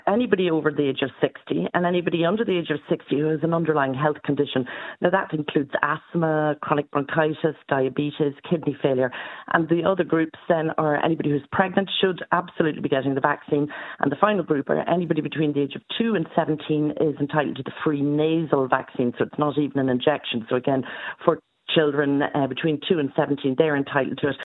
Monaghan based GP